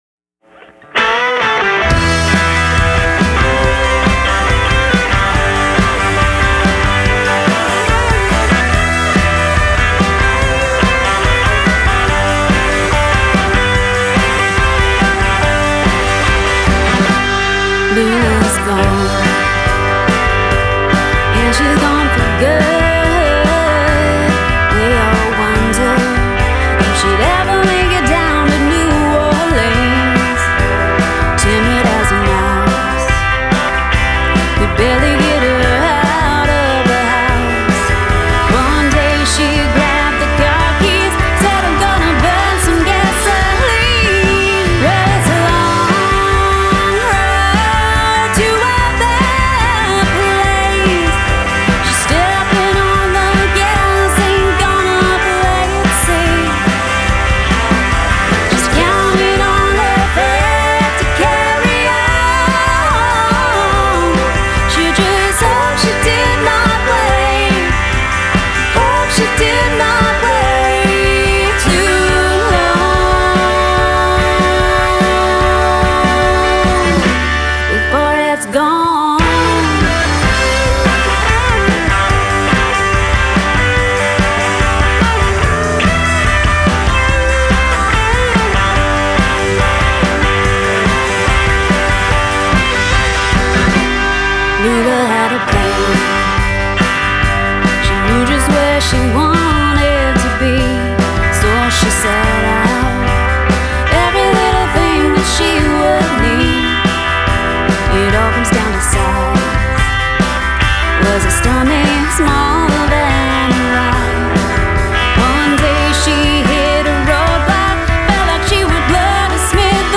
has a twangy-but-rocking vibe. These are good musicians.